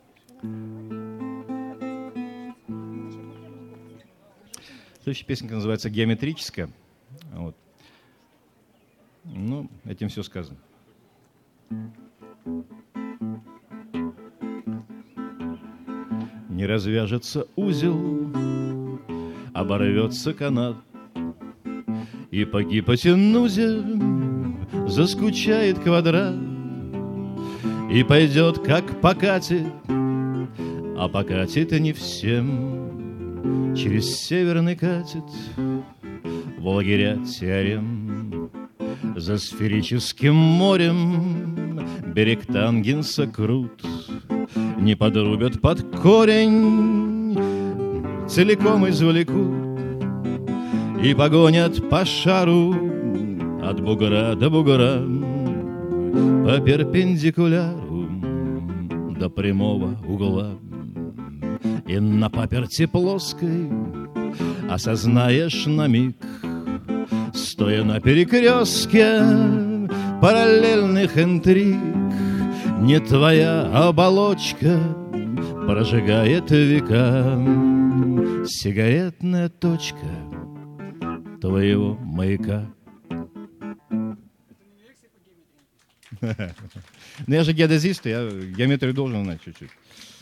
2005 - Граненый слёт